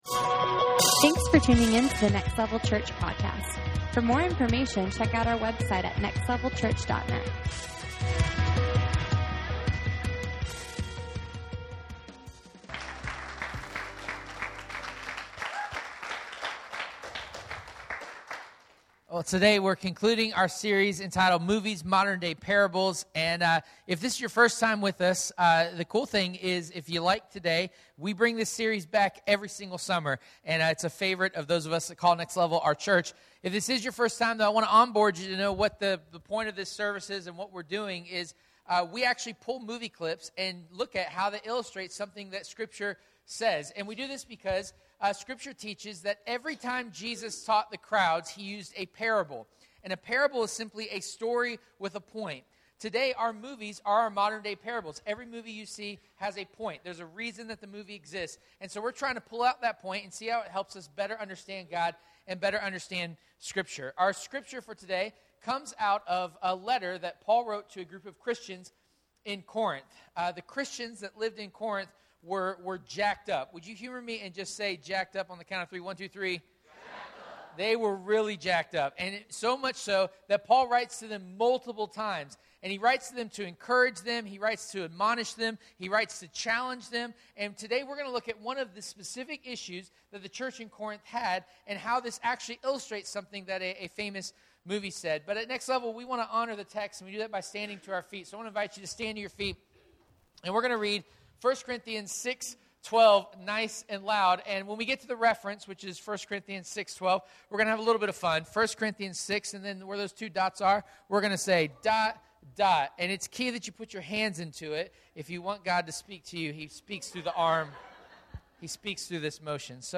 Modern Day Parables Service Type: Late Service « Movies